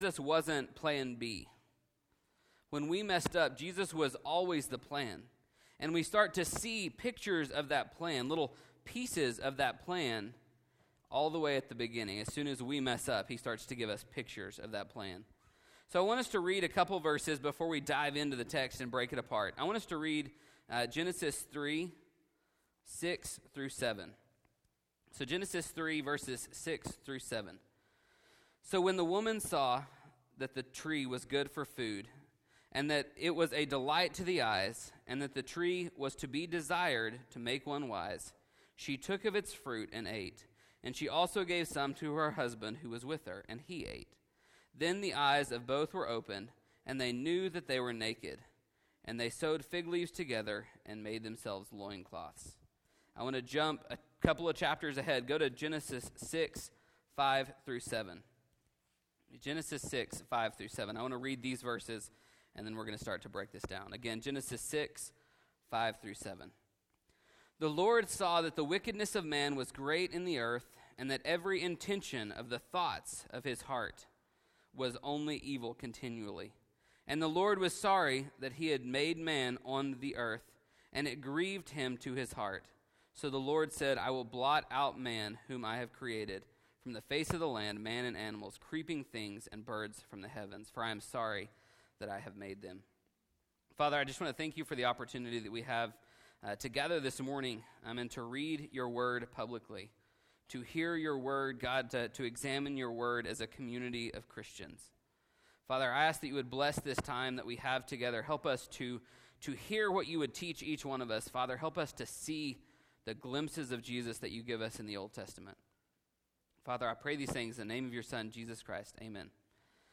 Glimpse: Hope in the Midst of Darkness – Week 1 of the Glimpse Sermon Series